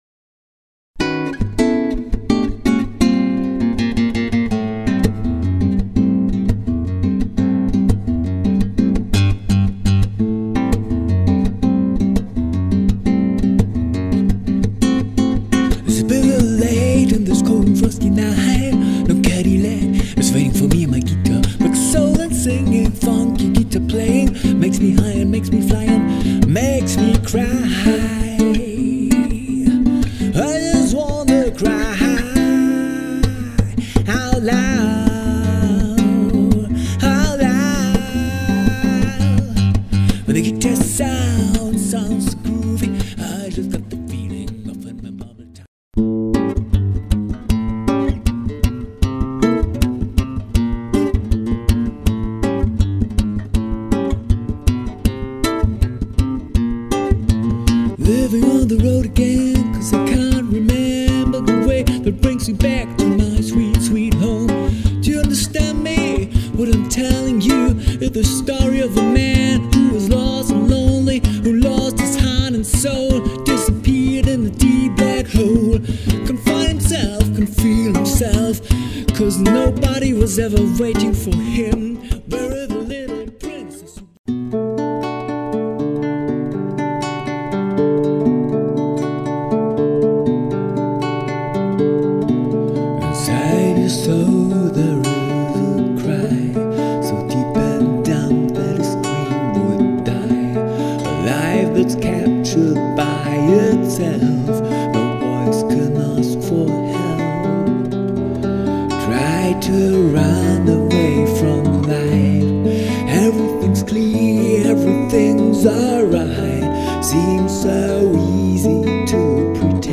Songs für Gesang und Gitarre